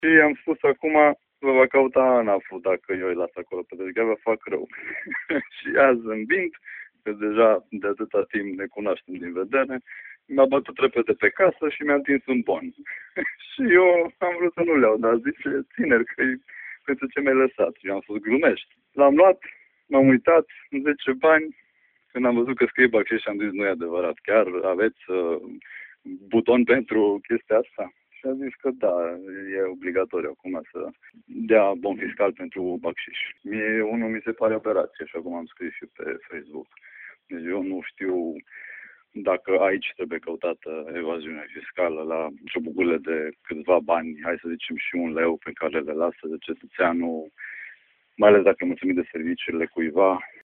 (declarație format AUDIO)